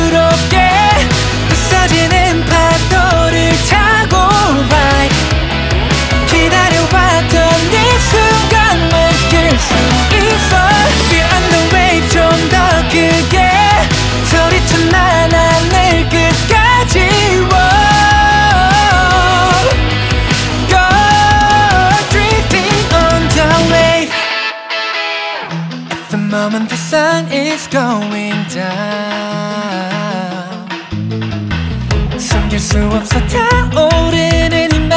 Жанр: Поп музыка / Соундтрэки
K-Pop, Pop, Soundtrack